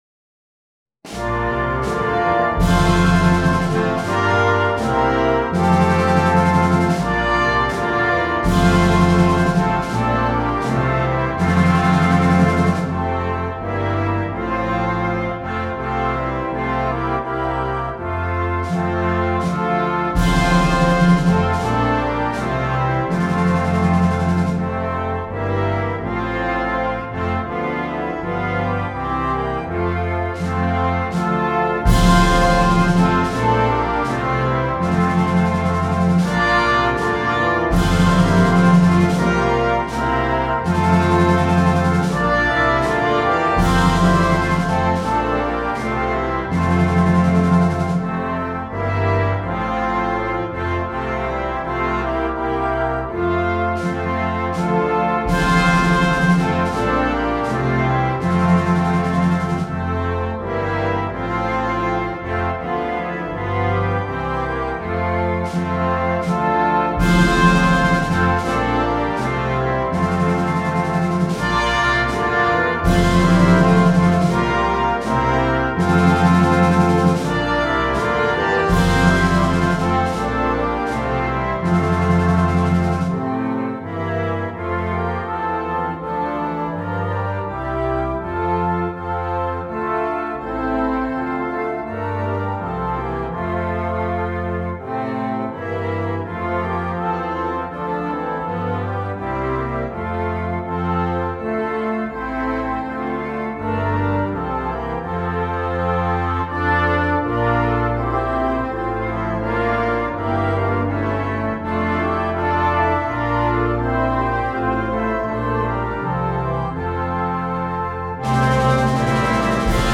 Full Concert Band
KeyF Major
Timpani
Side Drum / Cymbals / Bass Drum
Organ